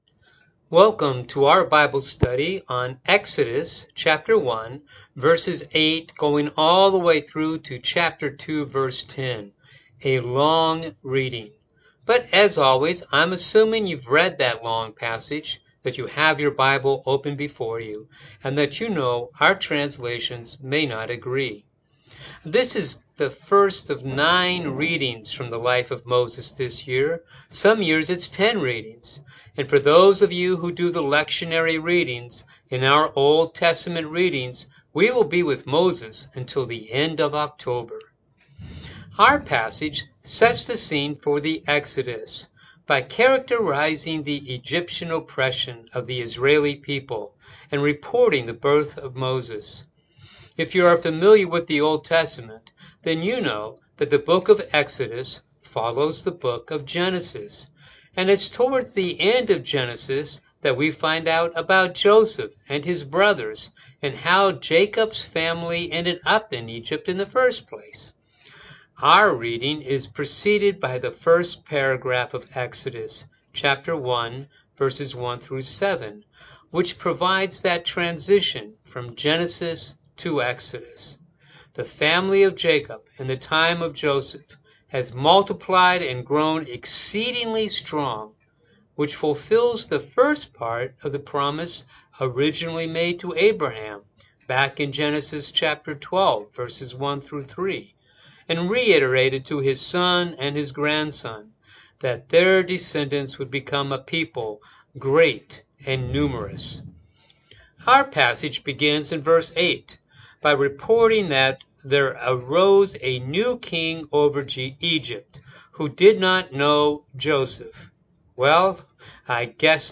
Bible Study for the August 23 Sermon